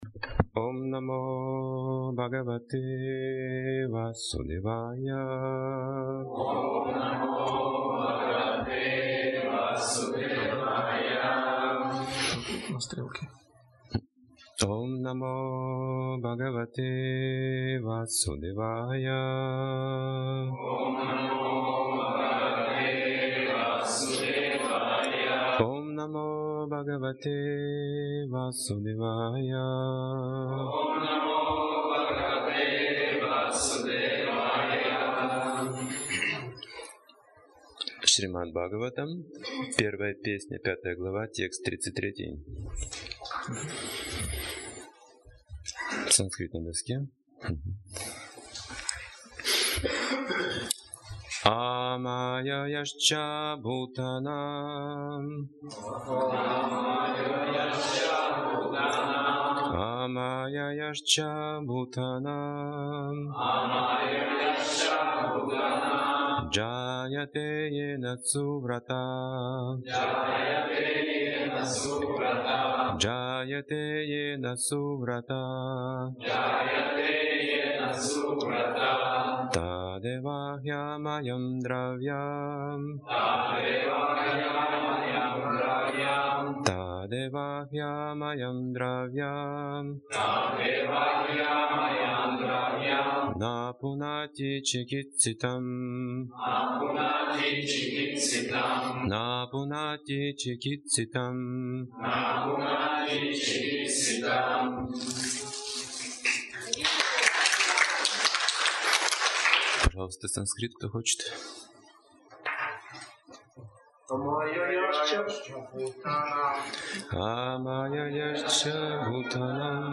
Темы затронутые в лекции Одухотворение деятельности Смерть Шрути История о царе Пуранжине Проявление любви в жизни Трансформация сознания Антипатия кчеловеку Регулирование гнева